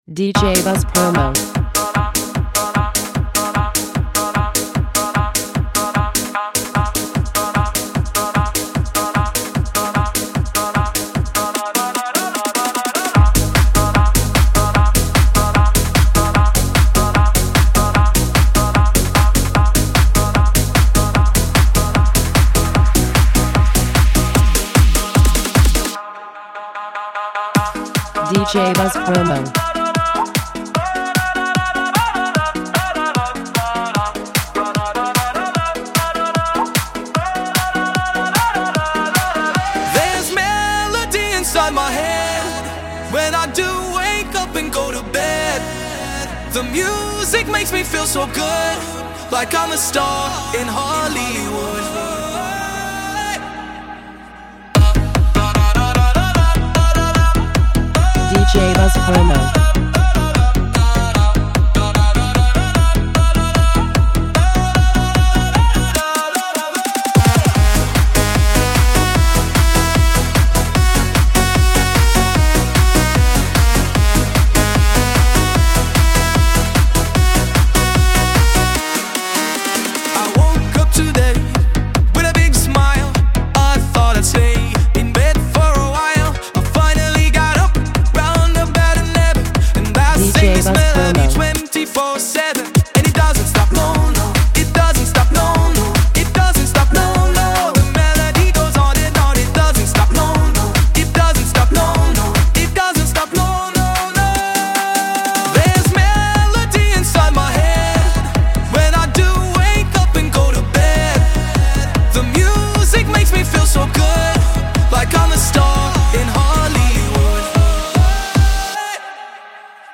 Prepare for the ultimate dance-floor hit!